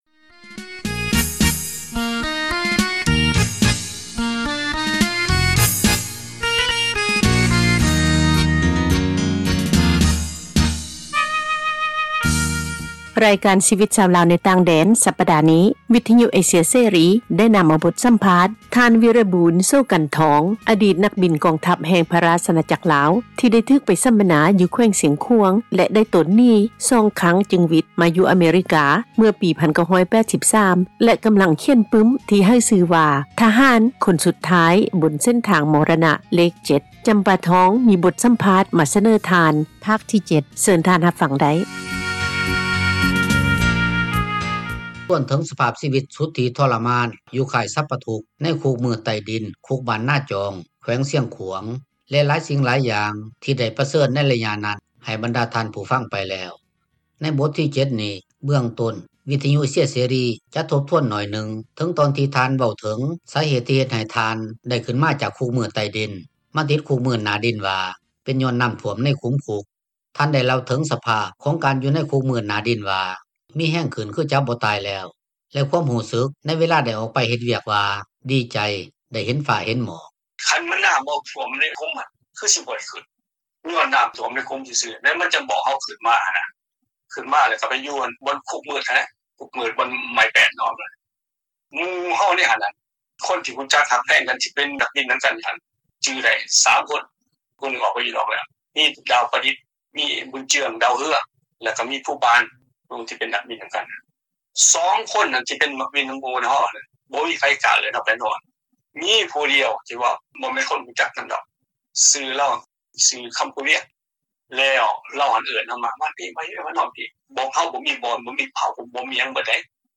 ມີບົດສັມພາດມາ ສເນີທ່ານ ພາກທີ 7 ໃນຣາຍການ “ຊີວິຕຊາວລາວ ໃນຕ່າງແດນ” ປະຈໍາສັປດານີ້ ເຊີນຮັບຟັງໄດ້.